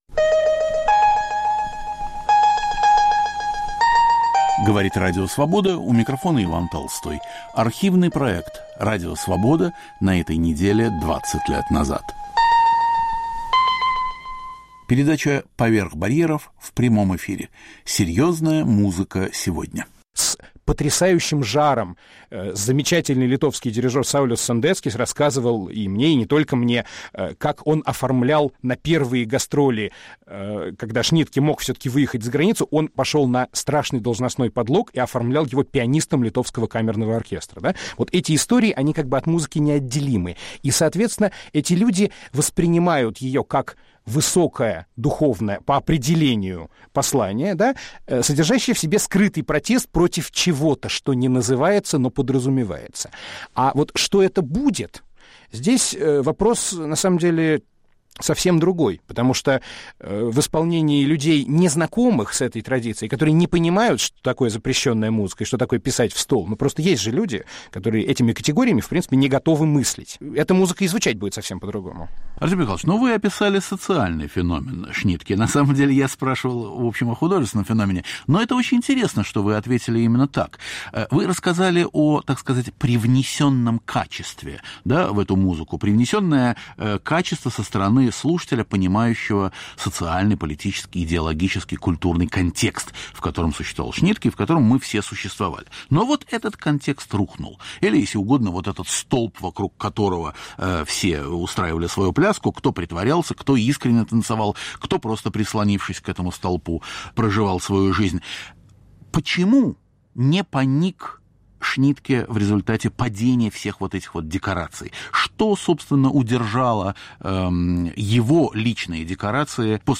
К 70-летию со дня рождения композитора Альфреда Шнитке. В передаче звучит Третий концерт для скрипки и камерного оркестра, 1978. В московской студии Радио Свобода виолончелист и музыкальный критик Артем Варгафтик.